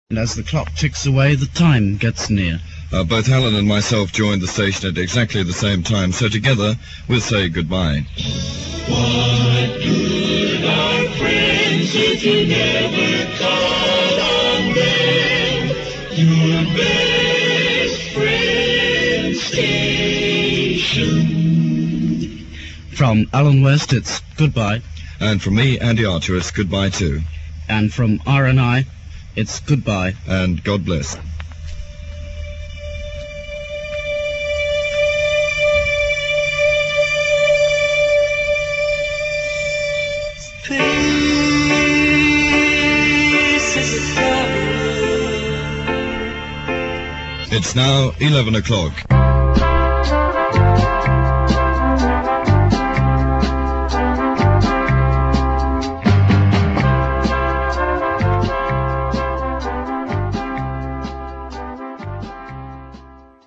They play the last record, Peace by Peter, and close the station down.